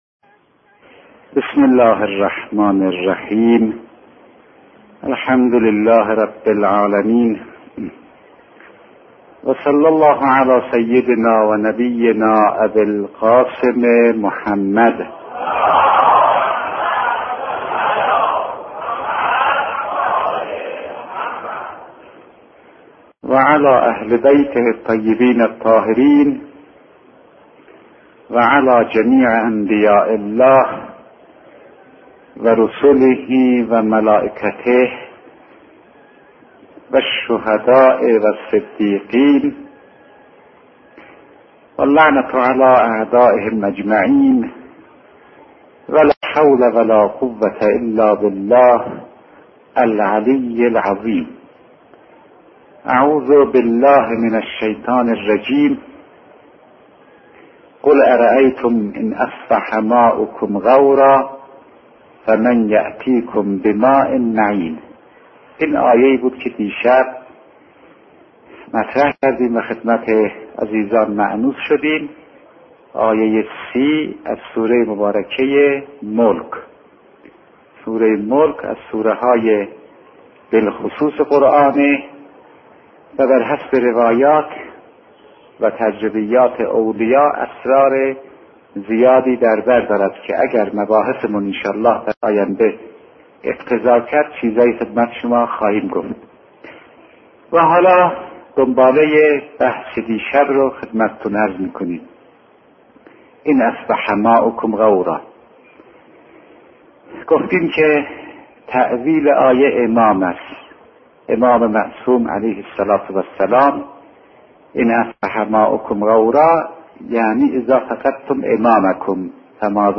بیانات عارف بزرگوار